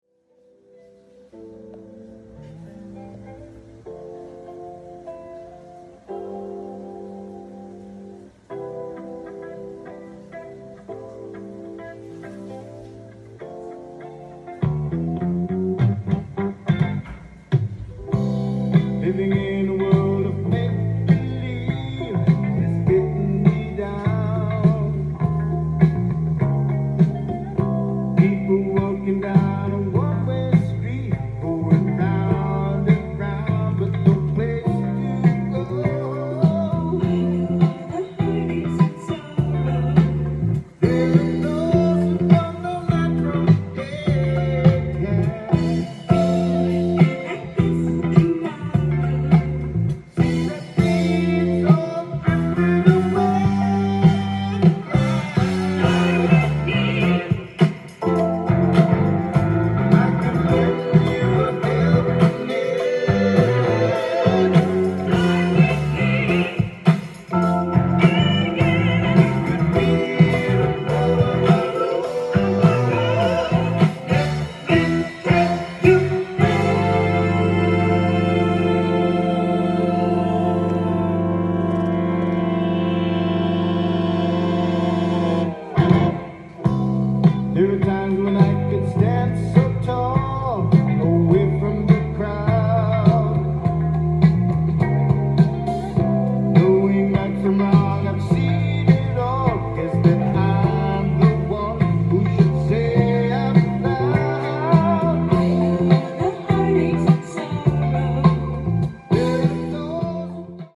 ジャンル：FUSION
店頭で録音した音源の為、多少の外部音や音質の悪さはございますが、サンプルとしてご視聴ください。